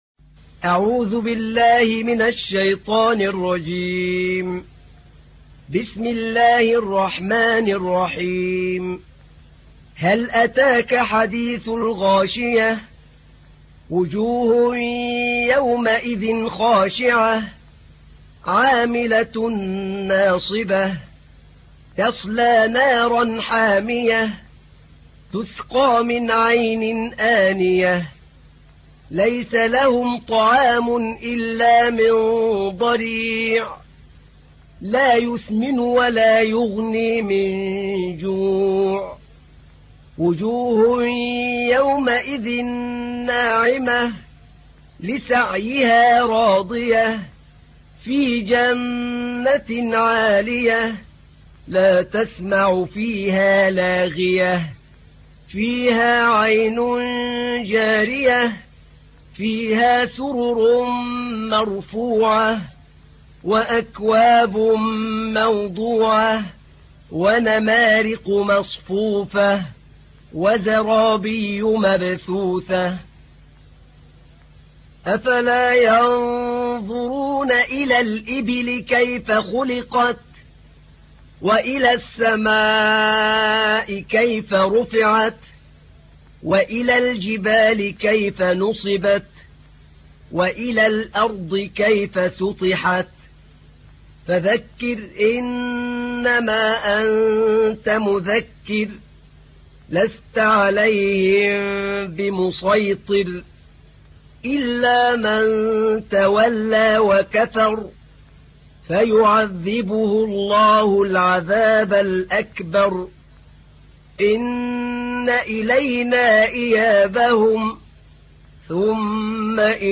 تحميل : 88. سورة الغاشية / القارئ أحمد نعينع / القرآن الكريم / موقع يا حسين